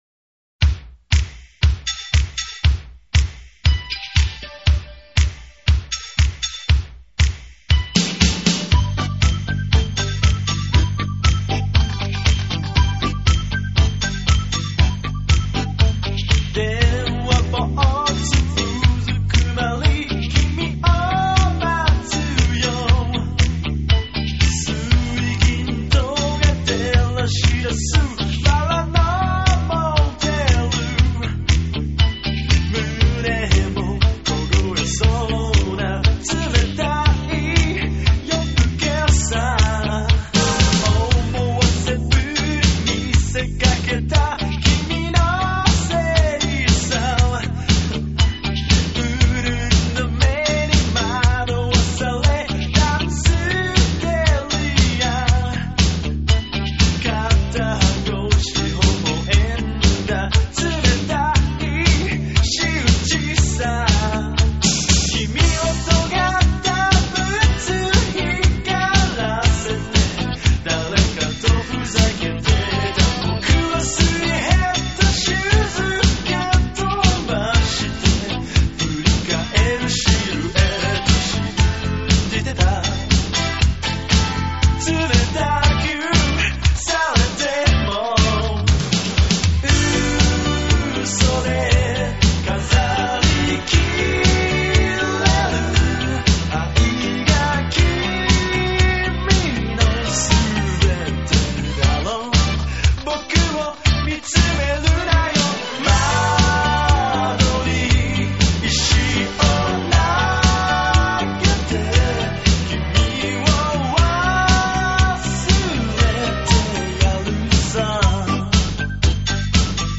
モノラルで低音質なのはご容赦。